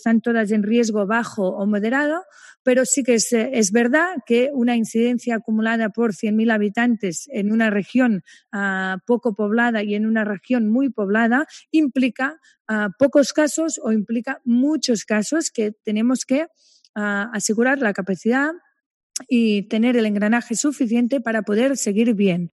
Alba Vergés, consellera de Salut explica por qué Barcelona no ha pasado de fase